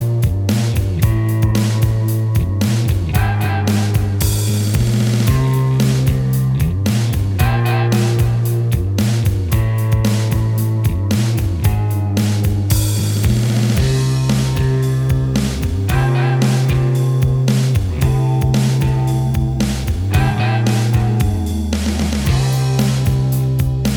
Minus Guitars Pop (1990s) 3:45 Buy £1.50